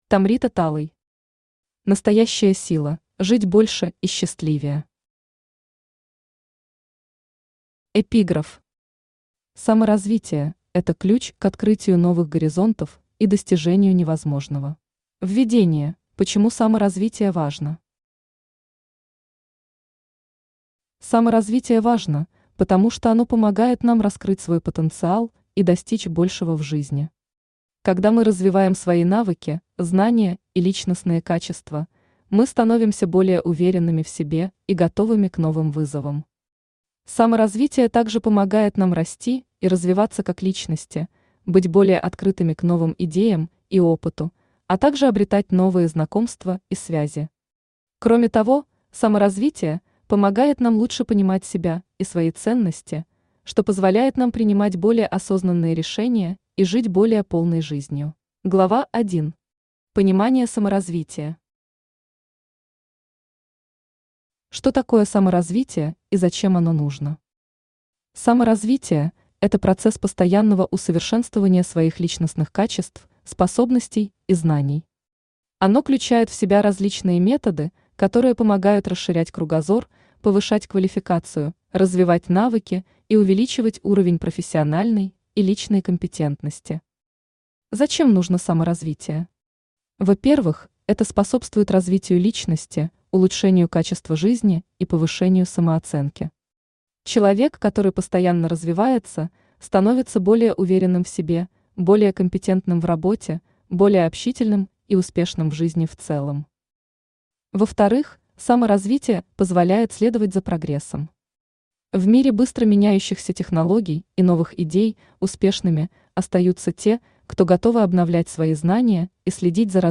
Aудиокнига Настоящая сила: Жить больше и счастливее Автор Tomrita Talay Читает аудиокнигу Авточтец ЛитРес.